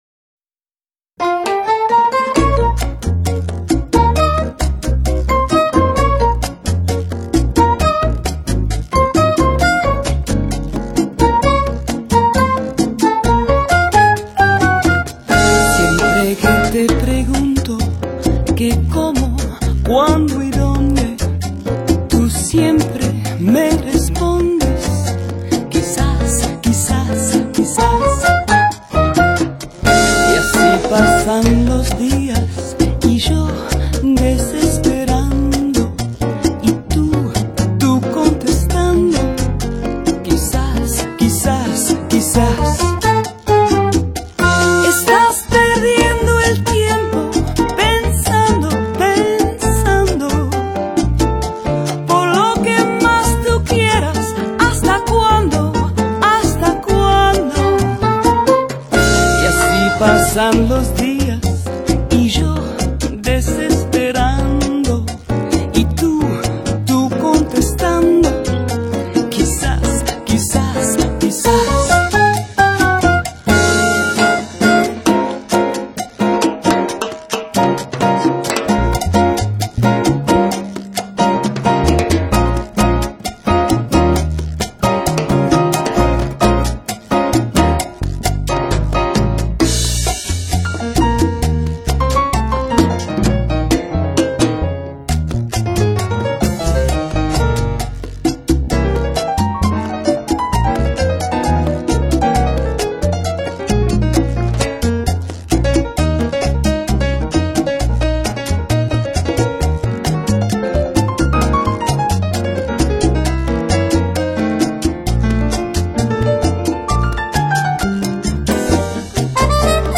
这张专辑17首歌曲除3首是附送的英文版本，其他14首全都是地道的拉丁语演唱，字正腔圆，韵味十足。